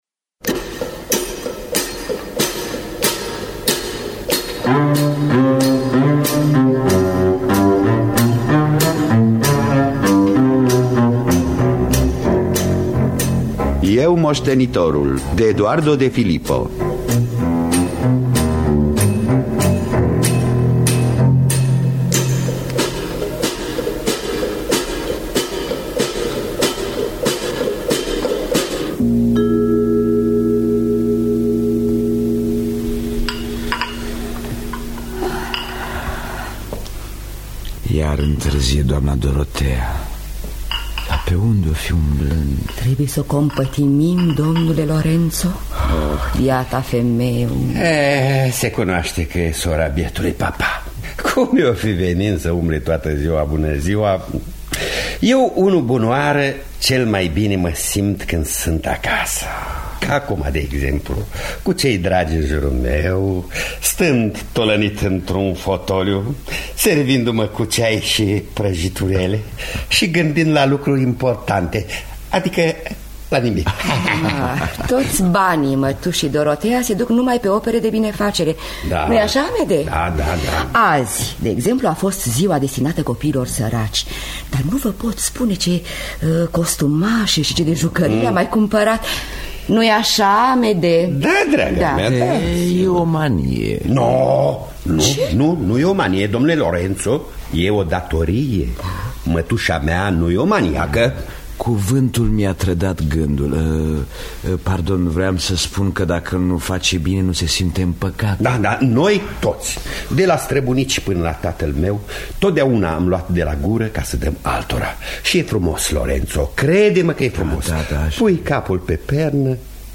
Eu, moștenitorul de Eduardo de Filippo – Teatru Radiofonic Online